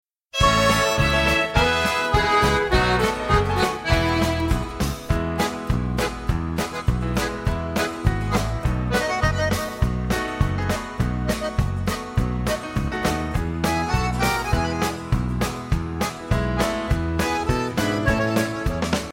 instrumental accompaniment music